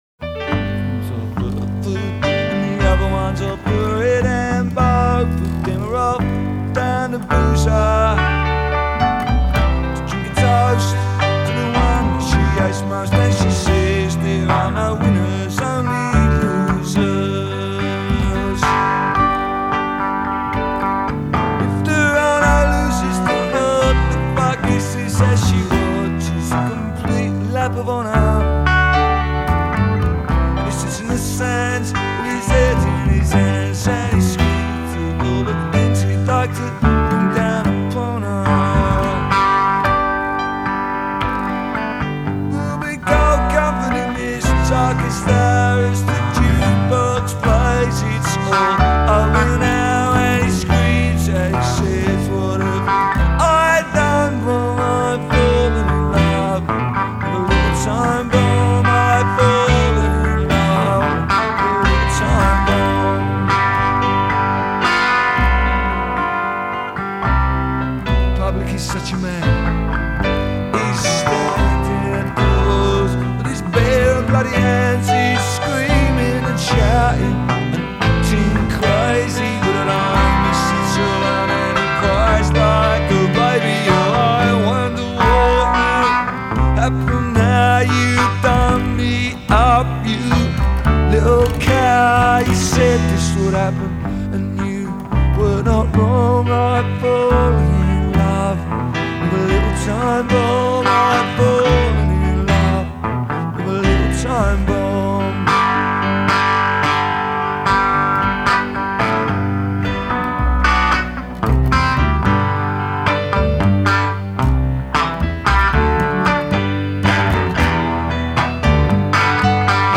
Today we have a demo recording of